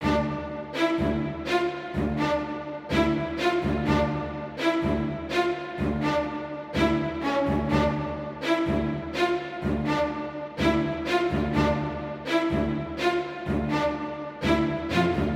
Tag: 125 bpm Cinematic Loops Strings Loops 2.58 MB wav Key : D